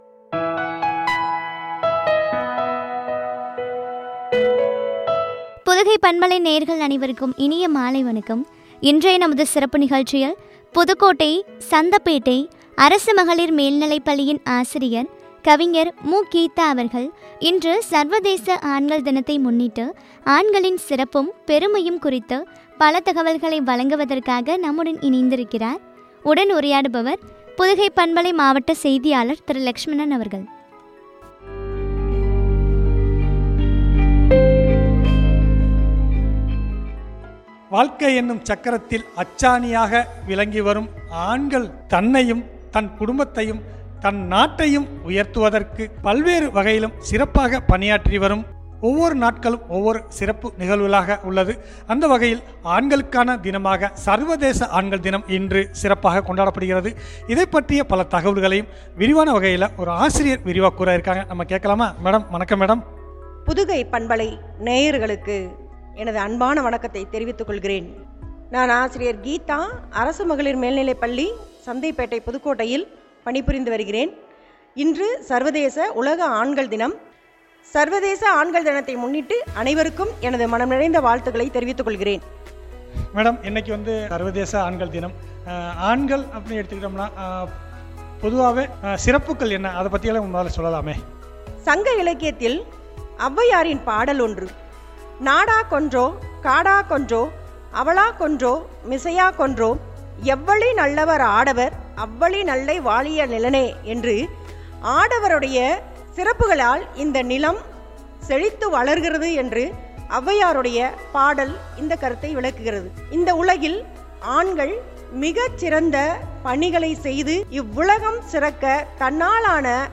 பெருமையும் பற்றிய உரையாடல்.